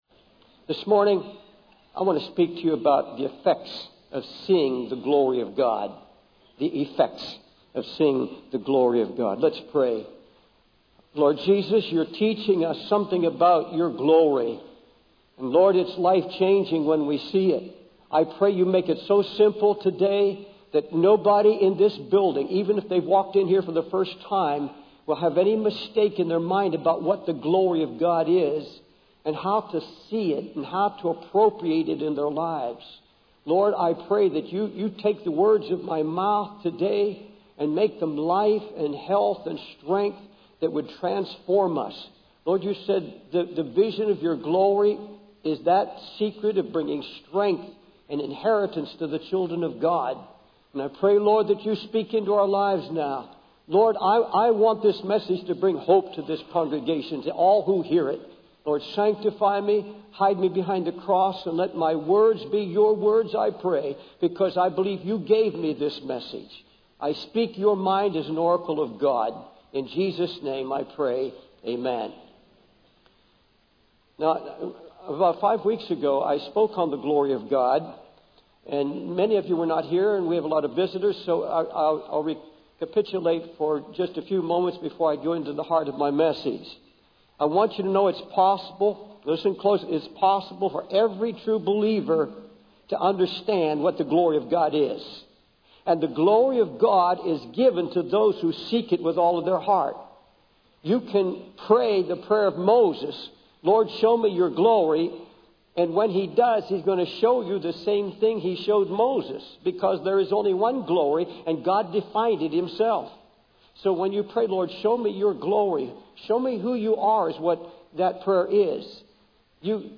In this sermon, the preacher discusses the purpose of God showing his glory to Moses.